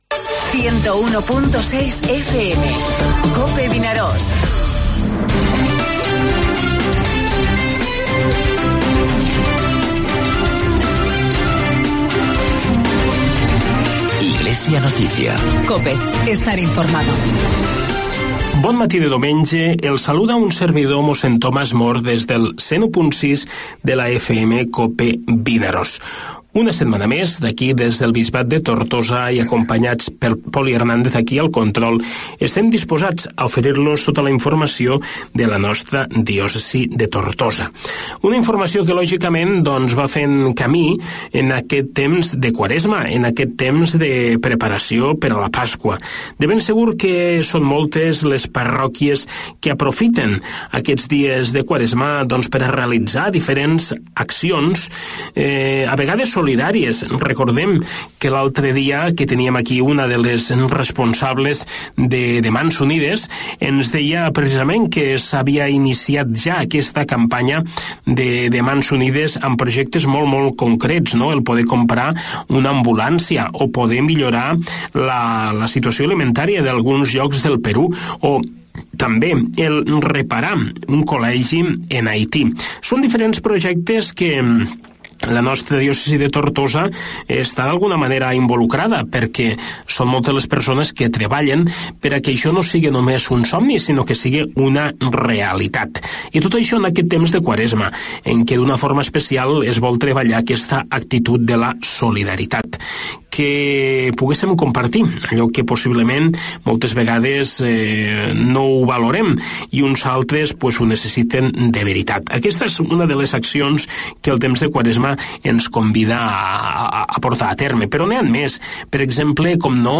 AUDIO: Espai informatiu del Bisbat de Tortosa, tots els diumenges de 9:45 a 10 hores.